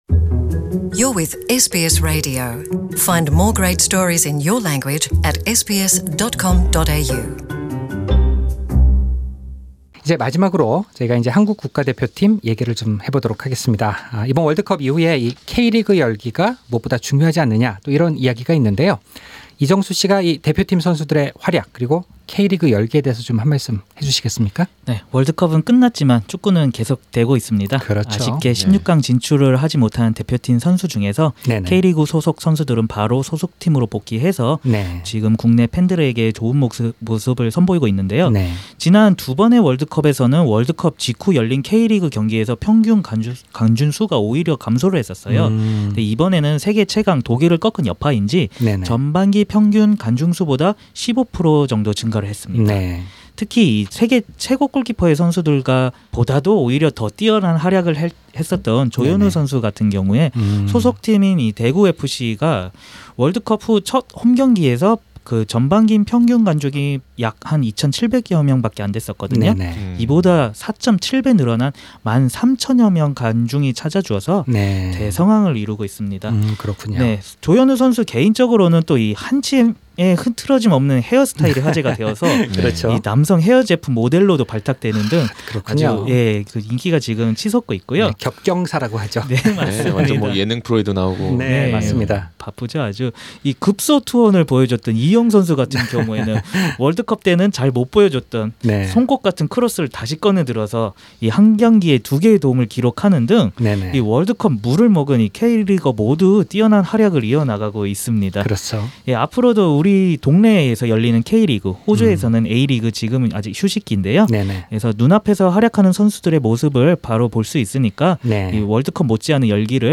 축구 전문 패널은 한국 축구의 발전을 위해서는 보다 장기적인 계획이 절실하다고 조언합니다.